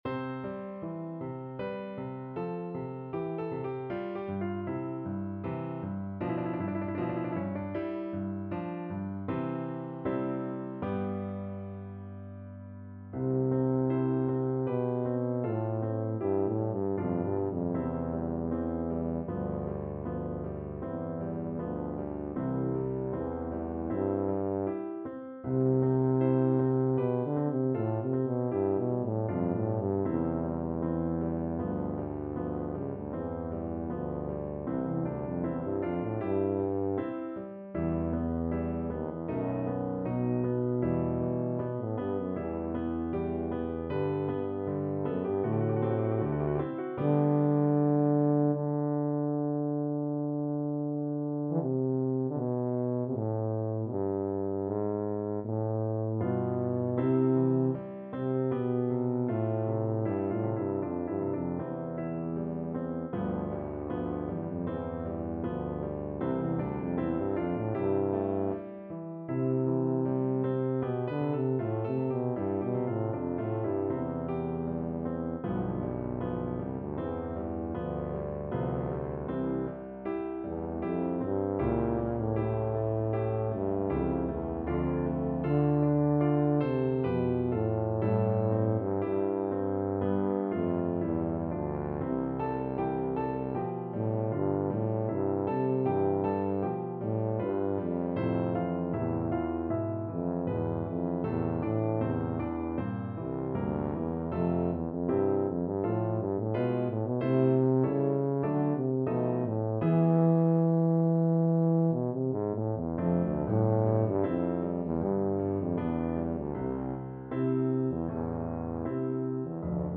Classical Donizetti, Gaetano Al Dolce Guidami from Anna Bolena Tuba version
Tuba
2/4 (View more 2/4 Music)
C major (Sounding Pitch) (View more C major Music for Tuba )
Larghetto cantabile =39
Classical (View more Classical Tuba Music)